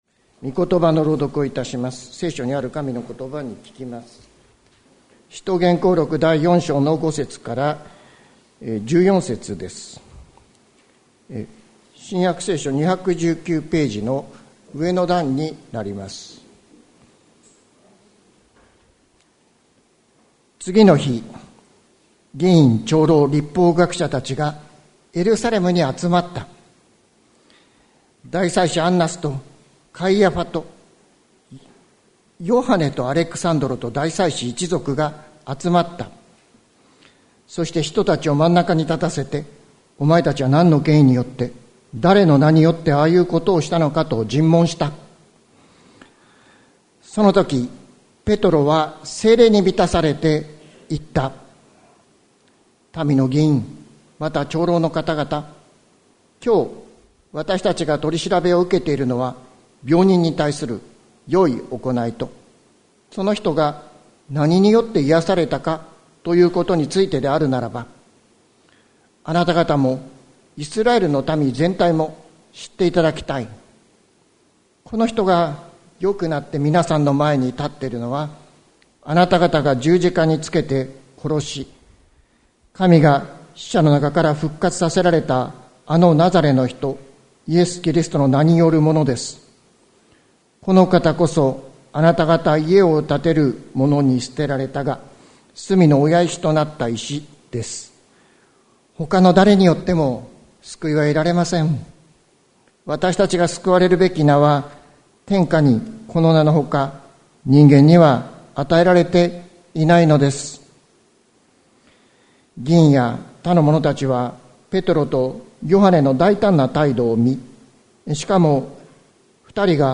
2024年07月28日朝の礼拝「わたしたちの救い」関キリスト教会
説教アーカイブ。